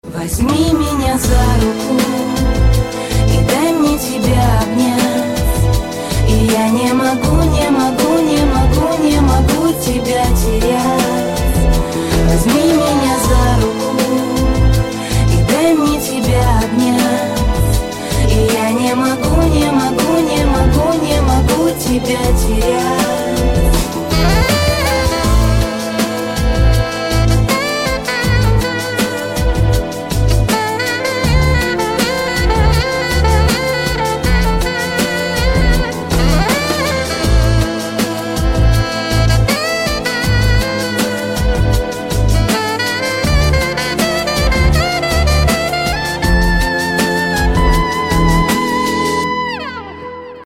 поп
женский вокал
dance
спокойные
Саксофон